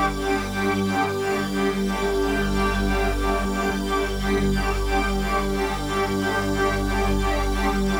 Index of /musicradar/dystopian-drone-samples/Tempo Loops/90bpm
DD_TempoDroneD_90-G.wav